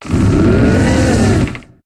Cri de Desséliande dans Pokémon HOME.